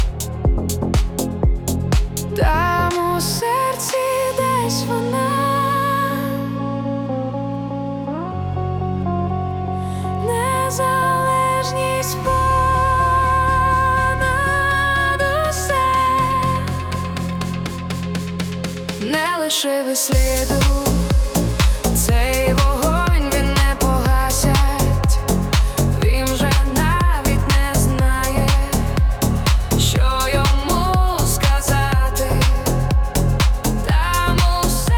Pop Dance
Жанр: Поп музыка / Танцевальные / Украинские